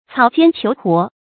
草間求活 注音： ㄘㄠˇ ㄐㄧㄢ ㄑㄧㄡˊ ㄏㄨㄛˊ 讀音讀法： 意思解釋： 草間：草野之中。形容只求眼前能馬馬虎虎活下去。